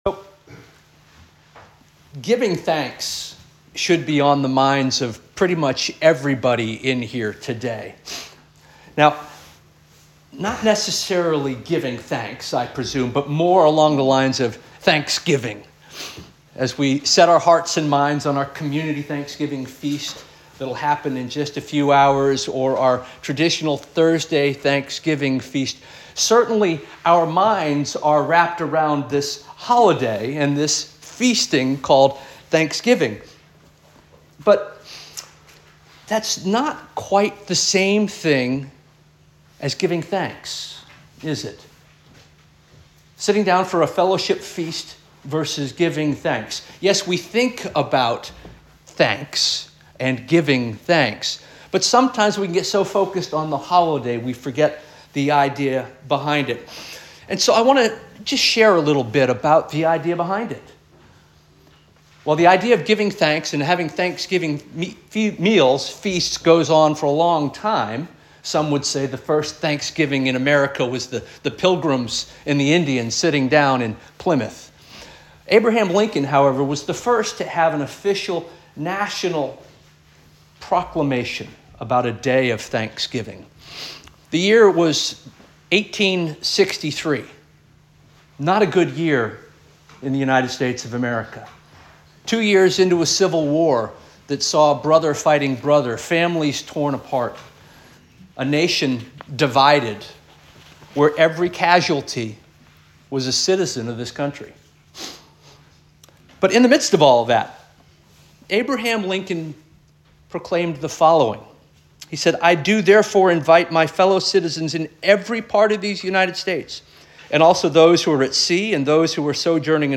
November 24 2024 Sermon